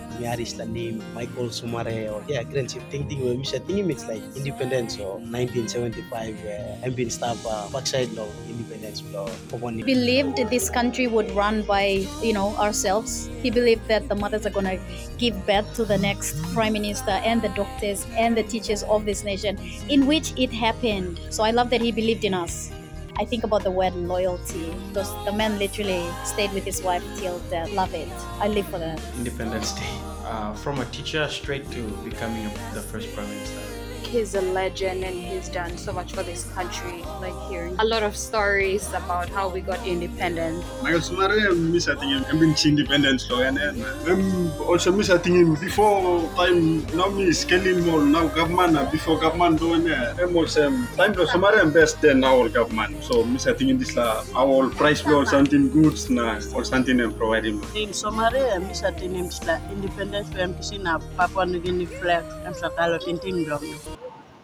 PUBLIC VOX ON THE SIGNIFICANCE OF REMEMBERING SIR MICHAEL
In honour of his legacy and to mark the significance of the day, PNG HAUSBUNG took to the streets of Port Moresby, asking the public to share their thoughts on what comes to mind when they hear the name of the late Grand Chief Sir Michael Thomas Somare and which of his many achievements stands out the most to them and here is what many of you had to say…